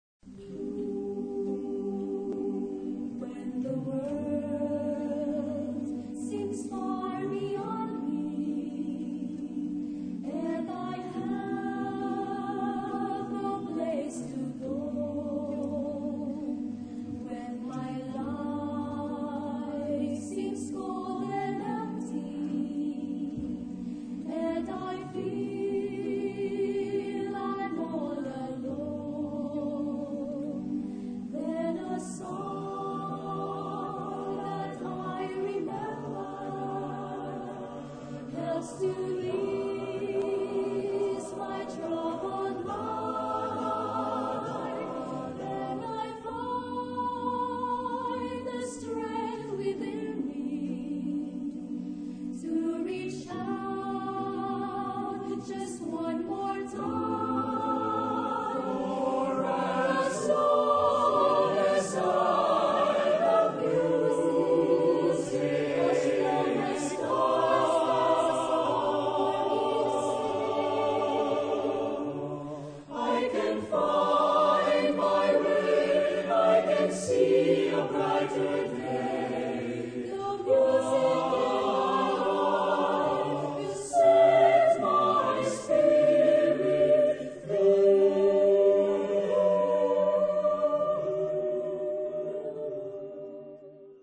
Type of Choir: SATB  (4 mixed voices )